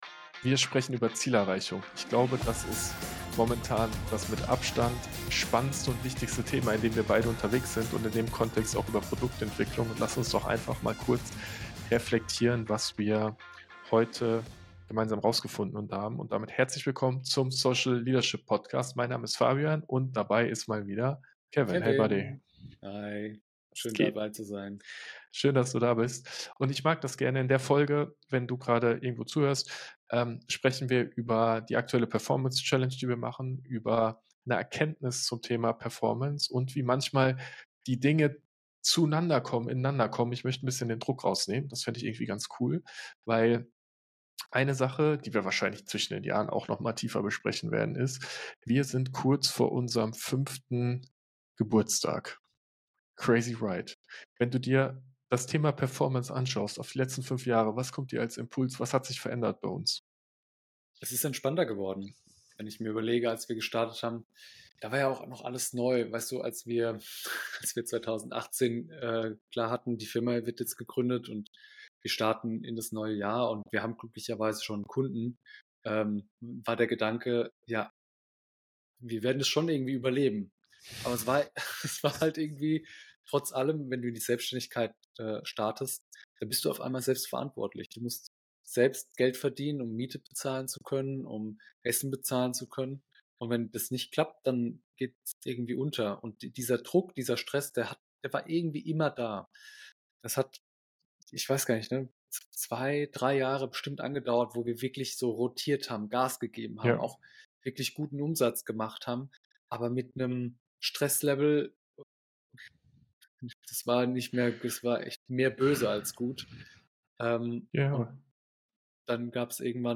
Wie du Ziele erreichen kannst - Gespräch aus dem Unternehmerleben | Folge 236 ~ Social Leadership Podcast